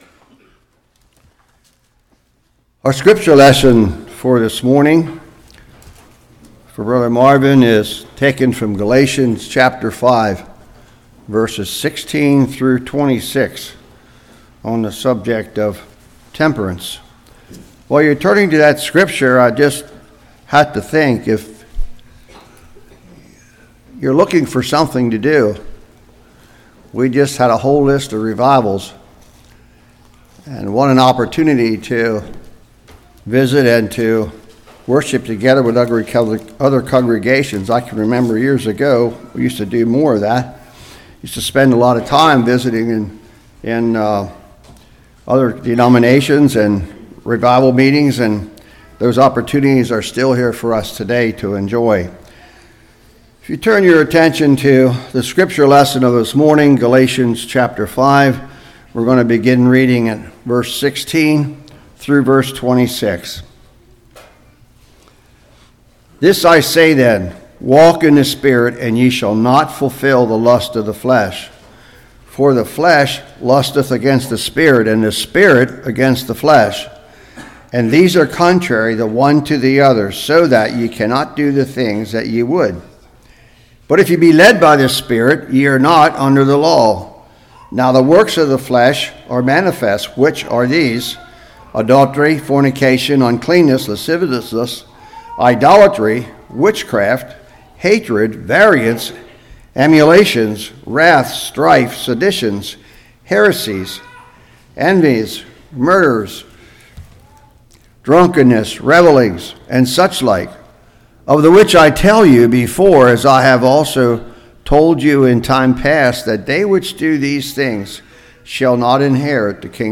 Galatians 5:16-26 Service Type: Morning https